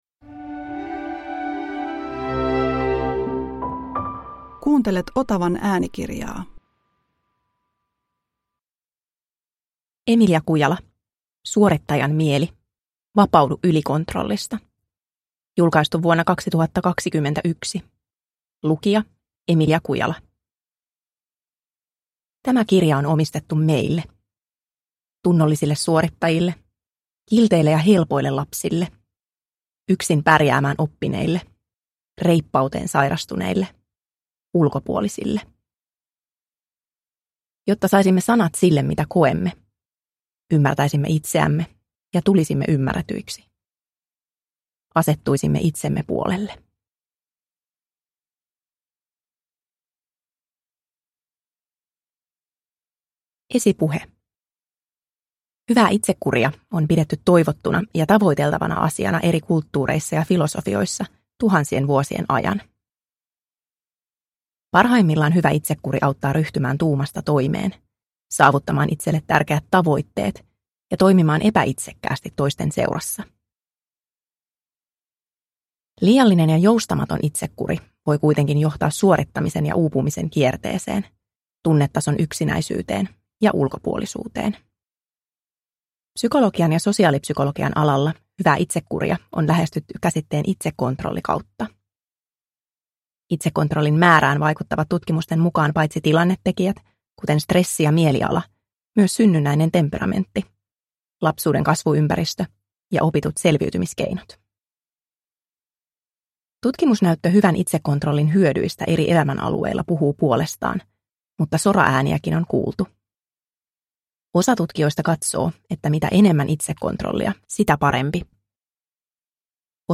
Suorittajan mieli – Ljudbok – Laddas ner